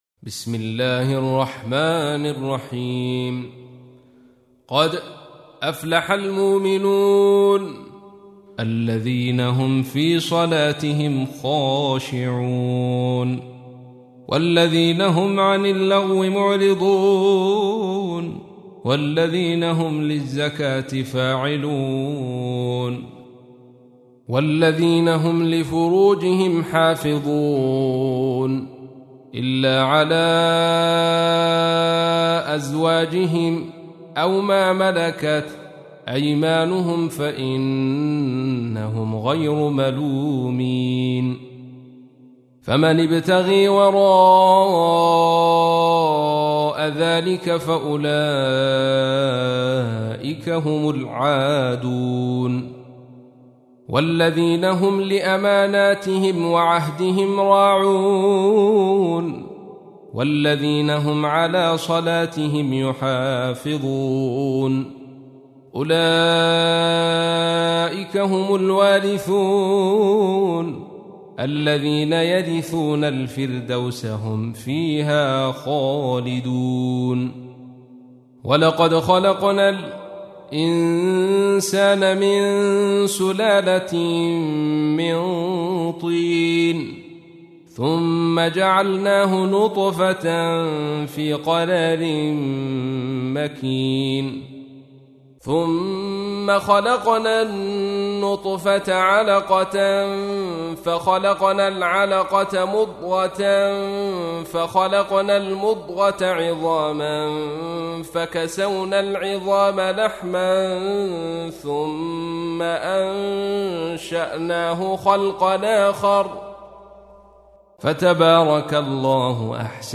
تحميل : 23. سورة المؤمنون / القارئ عبد الرشيد صوفي / القرآن الكريم / موقع يا حسين